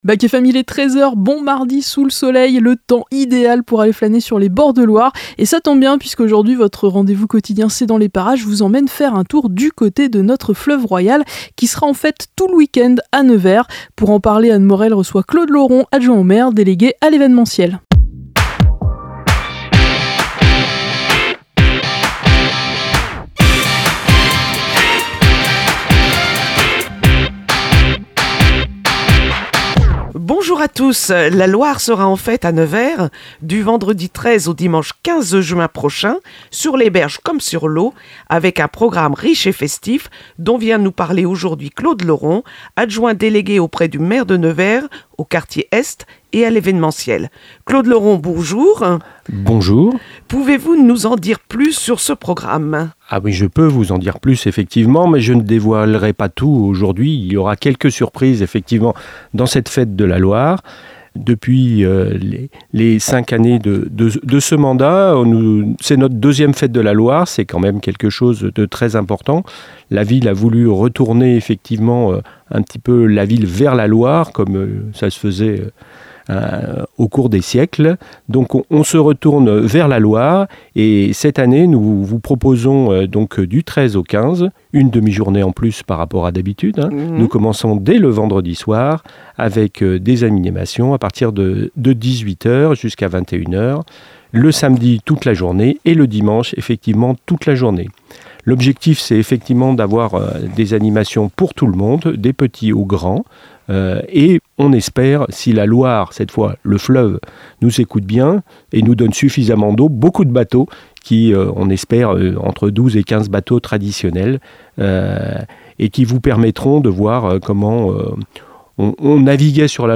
reçoit Claude Loron, Adjoint au Maire de Nevers délégué à l'événementiel. Ils reviennent sur le programme proposé tout le week-end Quai des Mariniers